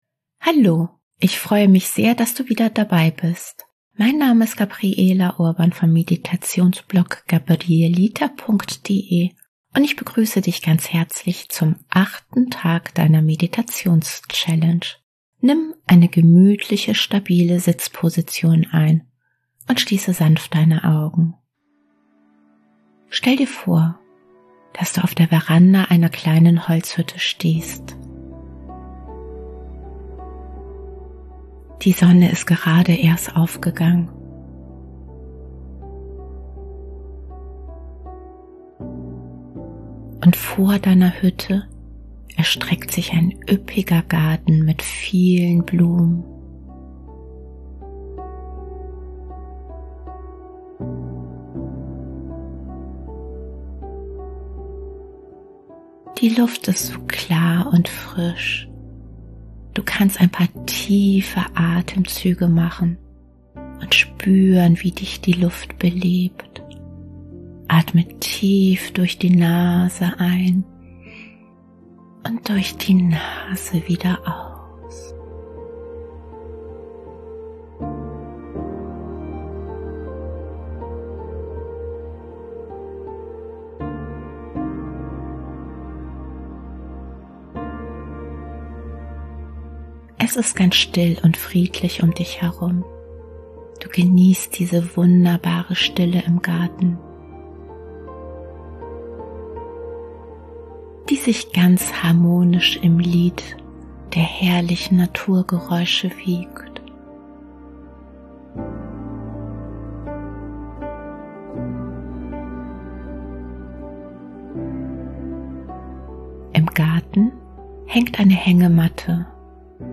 Meditations-Challenge
Traumreisen & geführte Meditationen